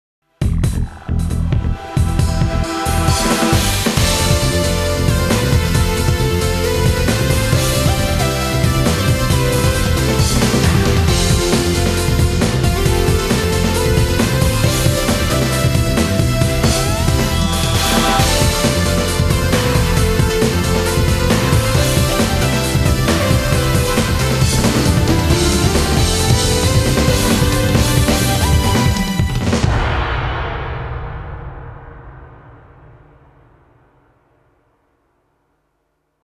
１つの曲に対し場面毎の展開に差を付けるためのネタ作りに注目？
かなり自由に作りました。